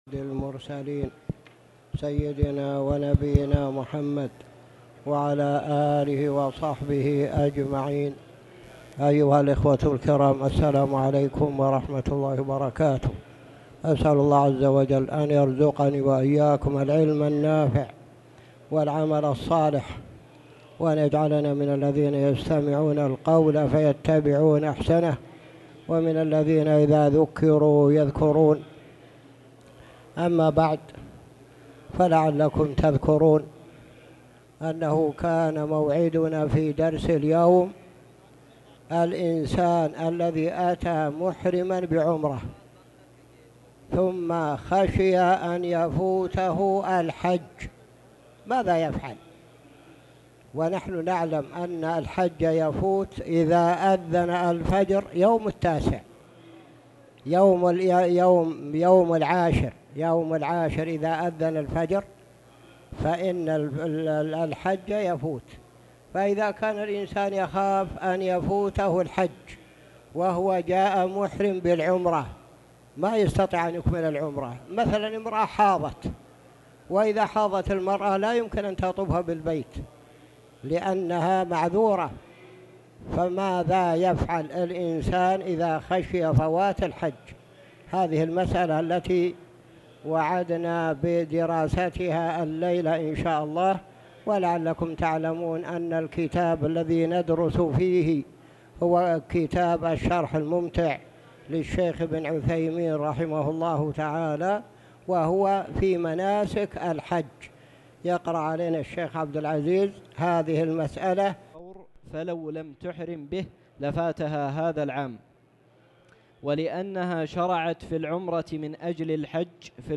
تاريخ النشر ١٧ ذو القعدة ١٤٣٨ هـ المكان: المسجد الحرام الشيخ